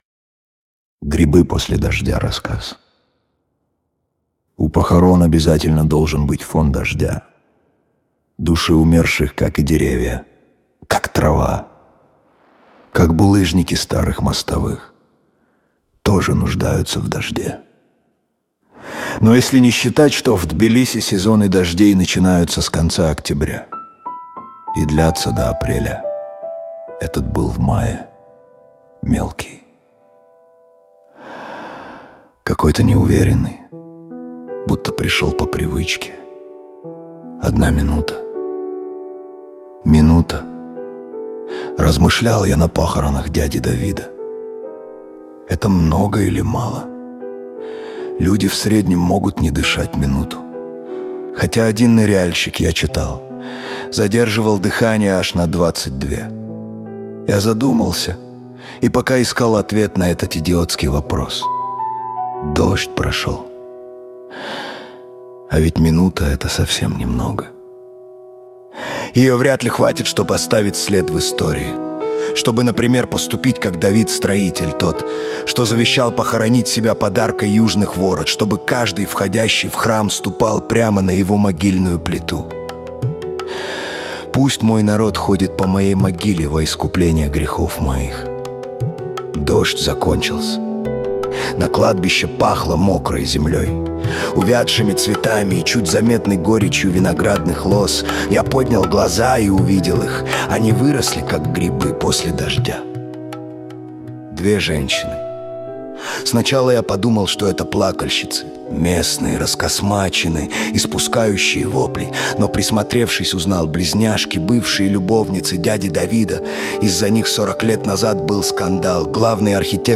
Аудио-нарратив